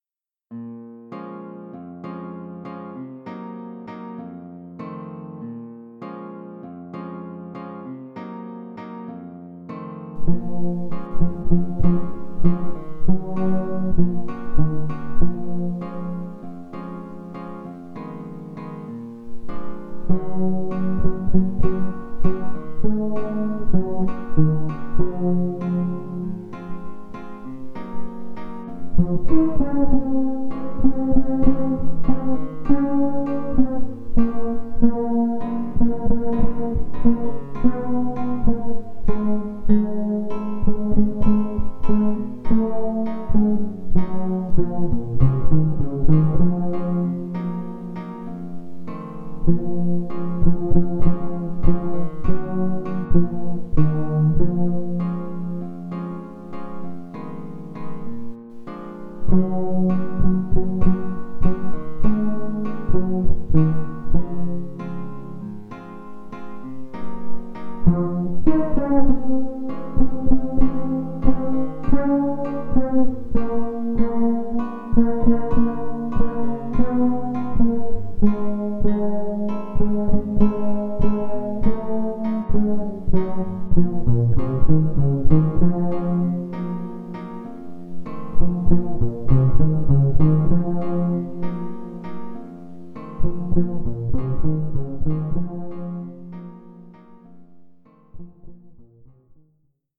[♪] The Quiet Ruins 2 Strings Bass & MIDI Guitar '22.08.17 宅録
最初、2弦ベースのメロにウッドベースを重ねようかと思ったのですが、暗くなりすぎたので打ち込みのギターにしてみました。